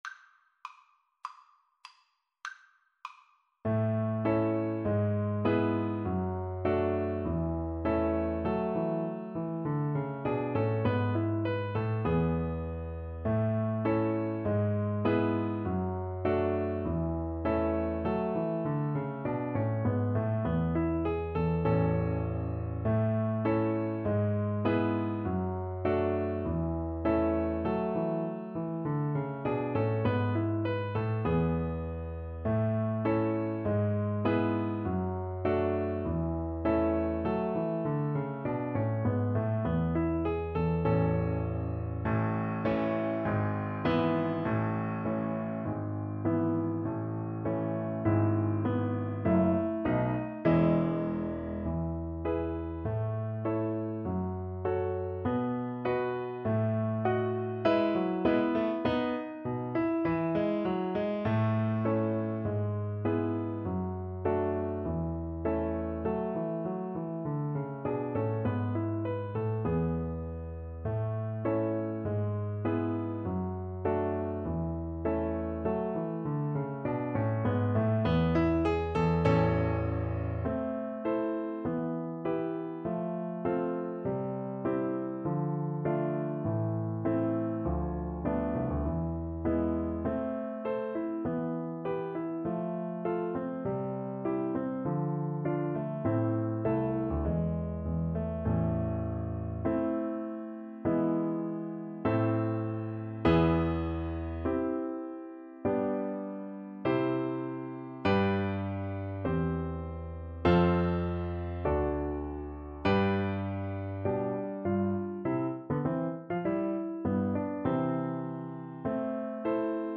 Play (or use space bar on your keyboard) Pause Music Playalong - Piano Accompaniment Playalong Band Accompaniment not yet available transpose reset tempo print settings full screen
~ = 100 Allegretto
A major (Sounding Pitch) (View more A major Music for Cello )
Classical (View more Classical Cello Music)